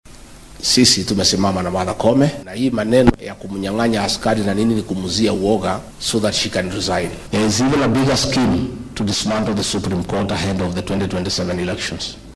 Gachagua ayaa hadalkan jeediyay xilli uu ku sugnaa kaniisadda Meru PCEA ee ismaamulka Meru wuxuuna madaxa cadaaladda ee dalka garsoore Martha Koome ugu baaqay in aynan is casilin.